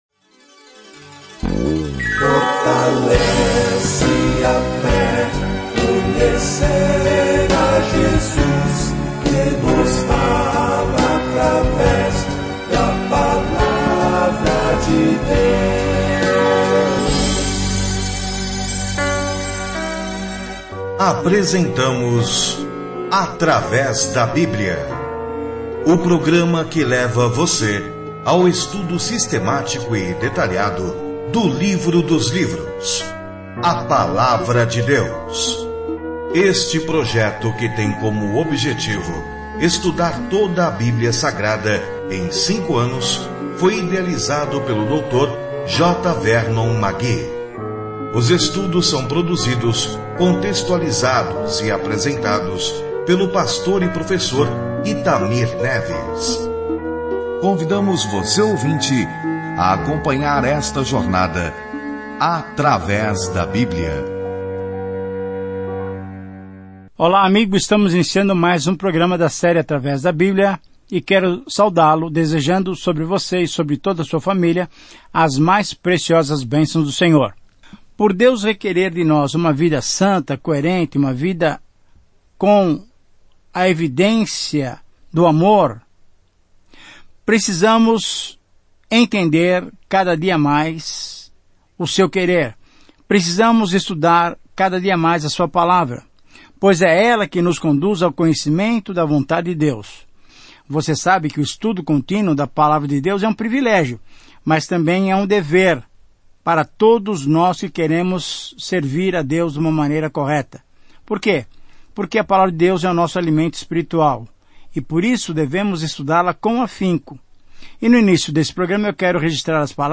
As Escrituras 2João 1:7 Dia 5 Começar esse Plano Dia 7 Sobre este Plano Esta segunda carta de João ajuda uma mulher generosa e uma igreja local a saber como expressar amor dentro dos limites da verdade. Viaje diariamente por 2 João enquanto ouve o estudo em áudio e lê versículos selecionados da palavra de Deus.